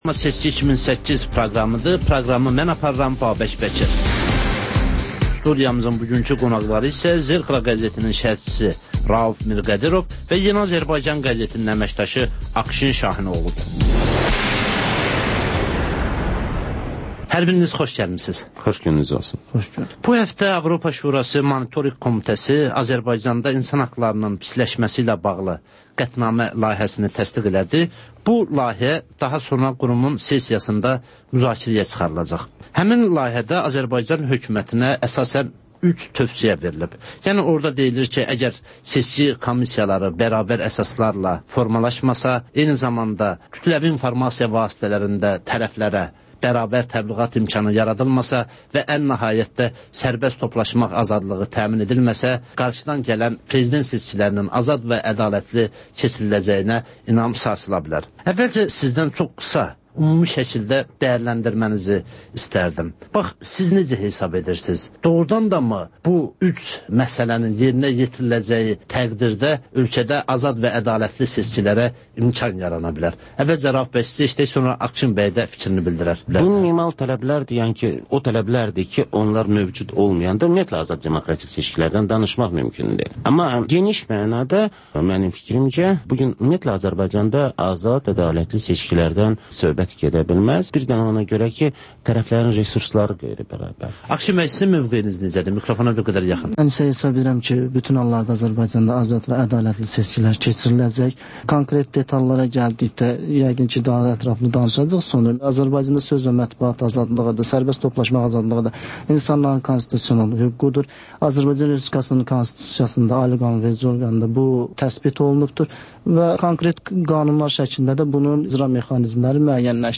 Ölkənin tanınmış simaları ilə söhbət